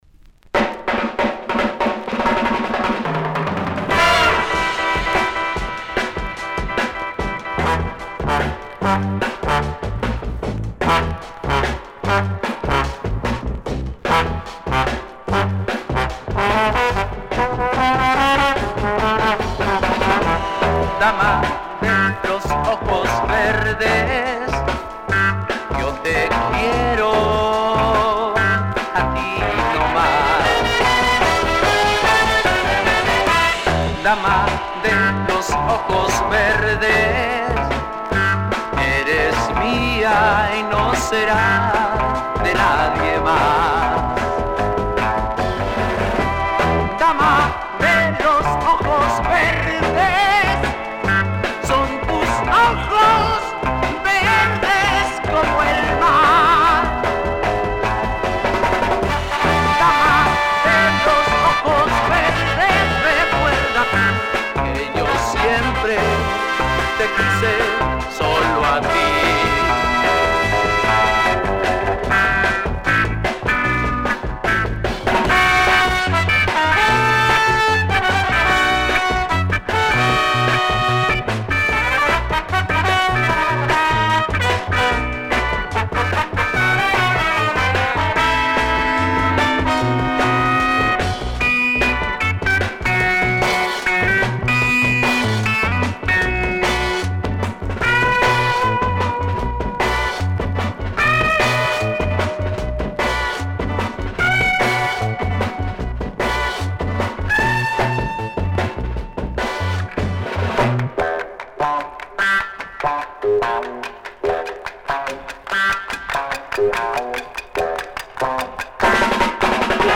Day 23: Los Covers
Published February 23, 2010 Garage/Rock Comments